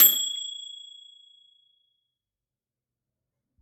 Microwave Ping
bell chime ding household kitchen microwave ping ring sound effect free sound royalty free Sound Effects